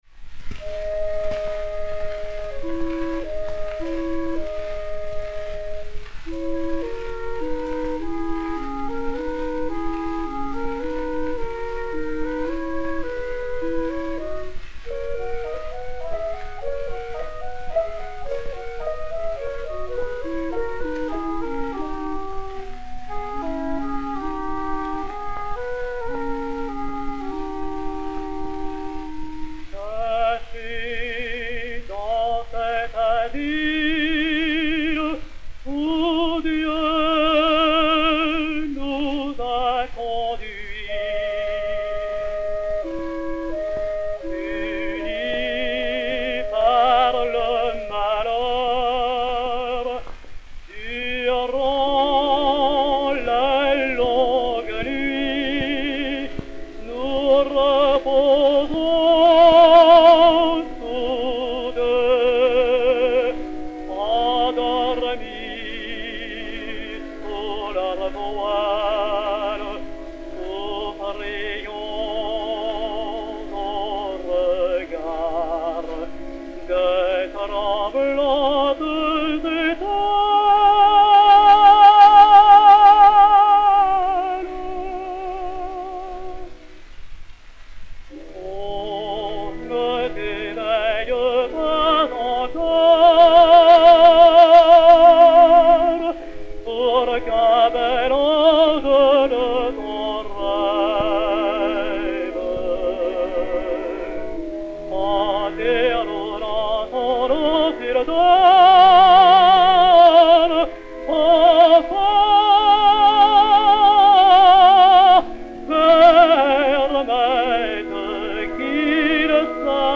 B-11392-2, édité sur Disque Pour Gramophone 4-32313, réédité sur Gramophone DA 143, enr. à Camden, New Jersey, le 18 décembre 1911